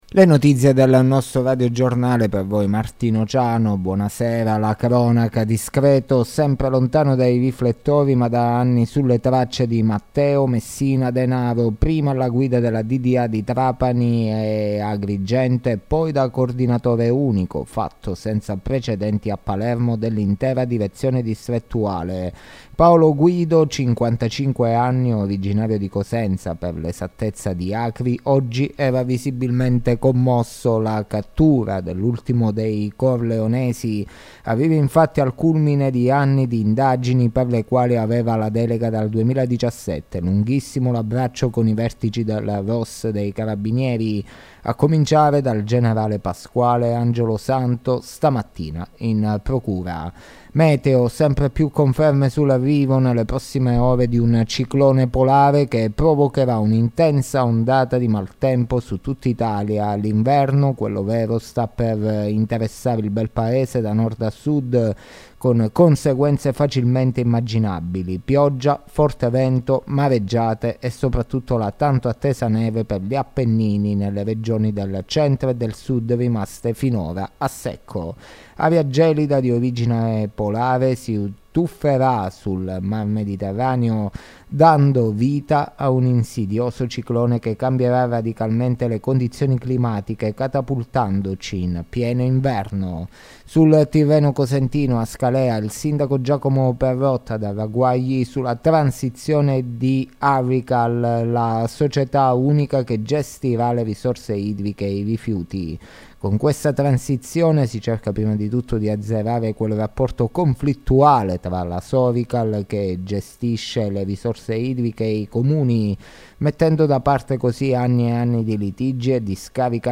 LE NOTIZIE DELLA SERA DI LUNEDì 16 GENNAIO 2023